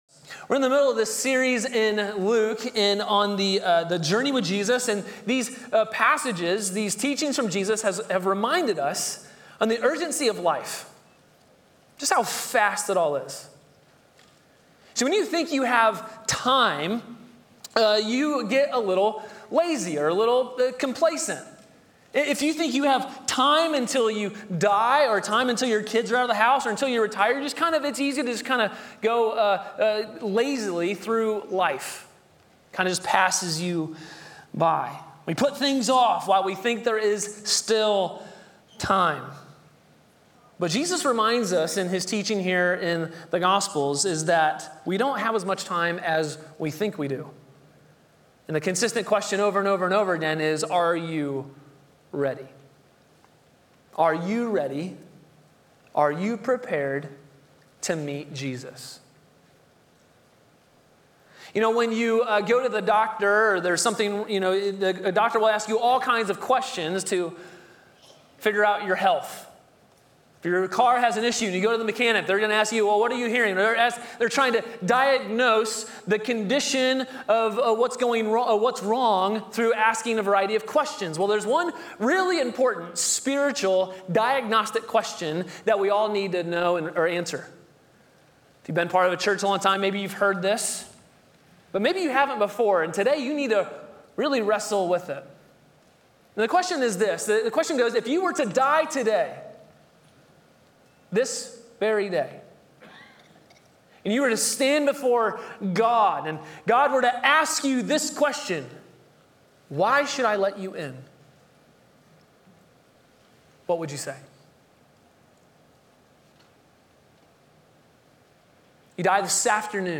A sermon from the series "The Journey With Jesus."